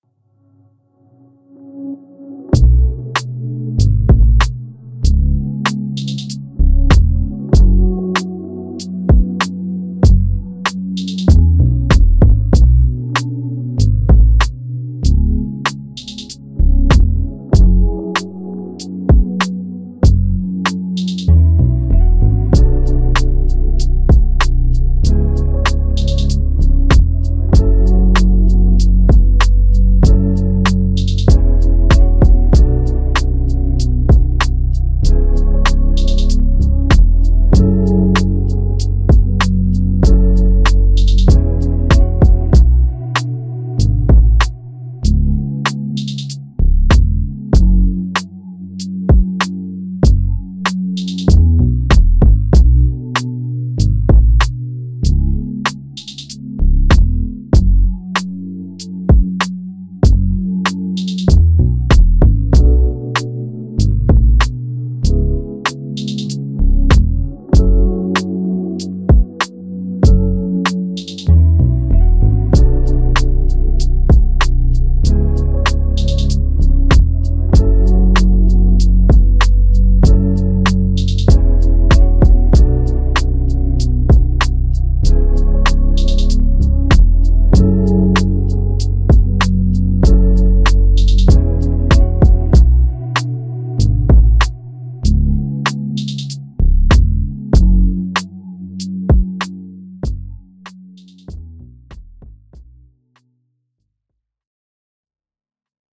R&B
F# Minor